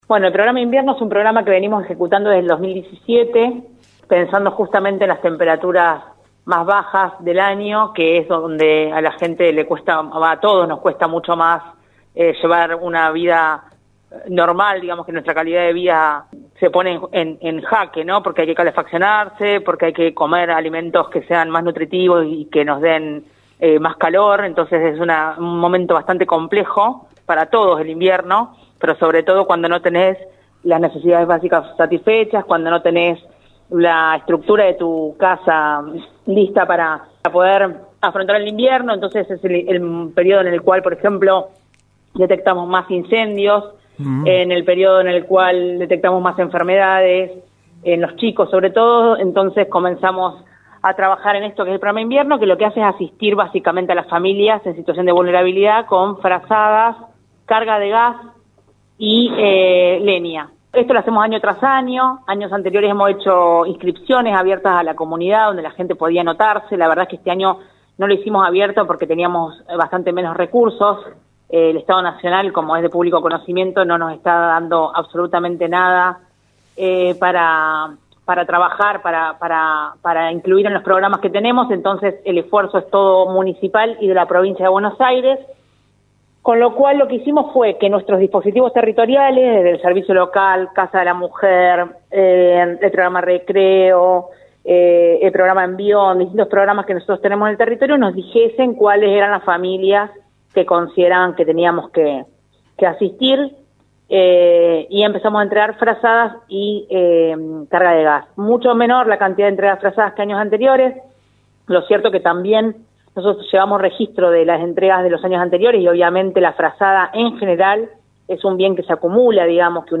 Lic. Jorgelina Silva, secretaria de desarrollo de la comunidad en Radio Universo